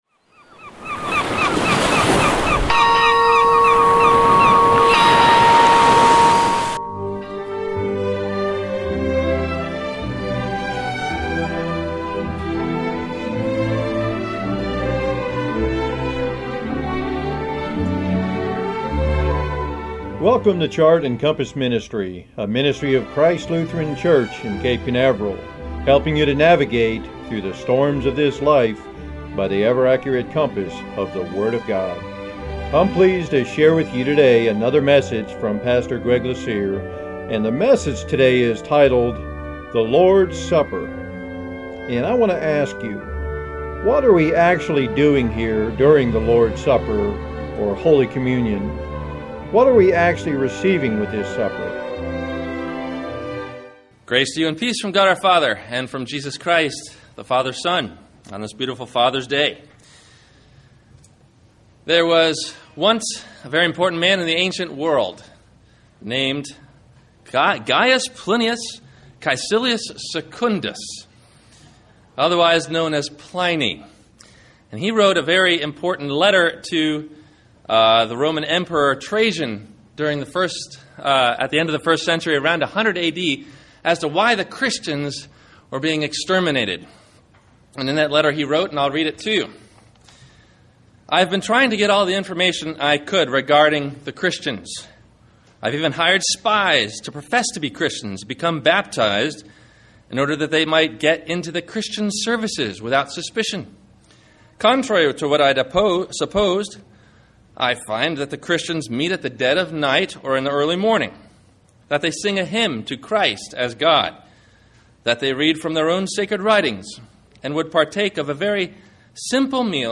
The Lord’s Supper (Holy Communion) – WMIE Radio Sermon – June 22 2015